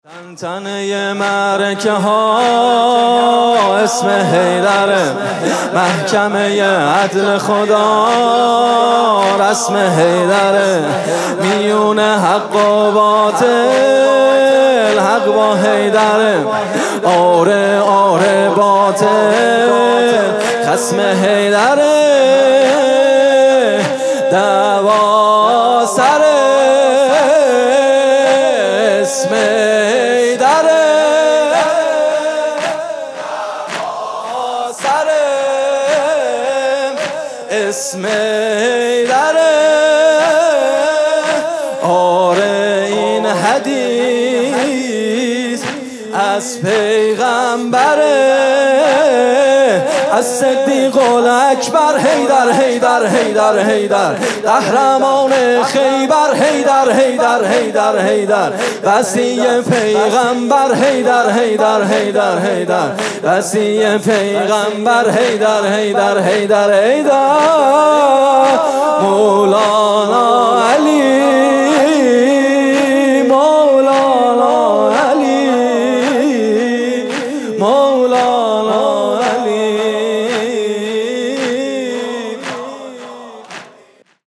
music-icon تک: دعواها سر اسم حیدره